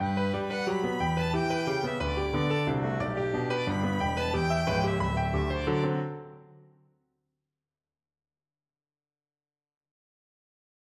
G장조 바이올린 소나타 Op. 78의 제1악장에서 브람스는 6/4 박자의 강세를 3+3과 2+2+2 사이에서 앞뒤로 바꾸거나, 바이올린과 피아노에서 둘 다를 중첩시키는 방식으로 음악 구절을 정교하게 다룬다.[5]